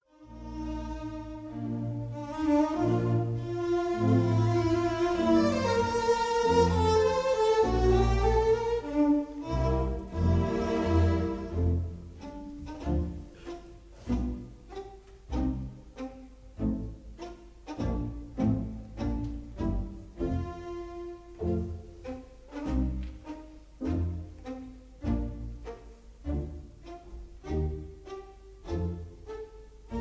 Recorderd live at the 1st Aldeburgh
Jubilee Hall, Aldeburgh, Suffolk